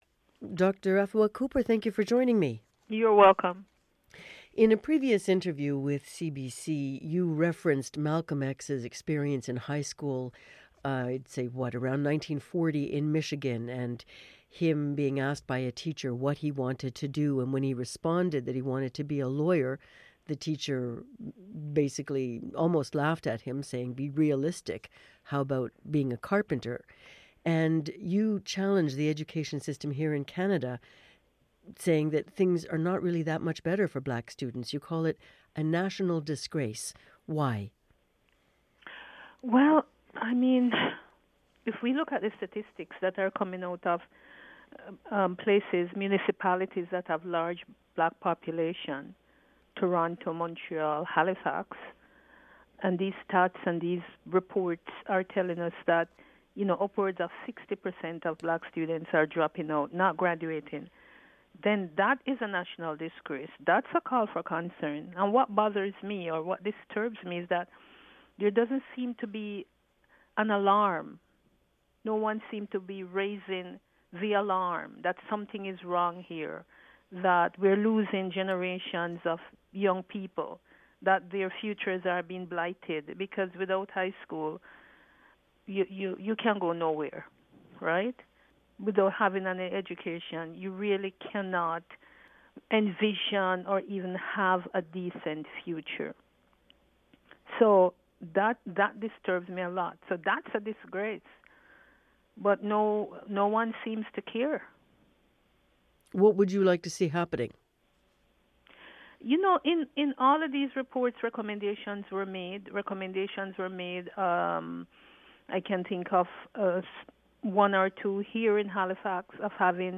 Interview-Professor-Afua-Cooper.mp3